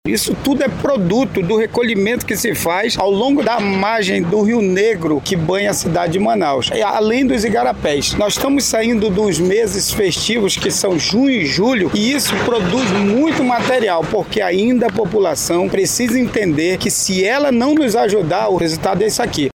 O subsecretário destaca, ainda, que durante alguns períodos do ano, a quantidade de lixo aumenta.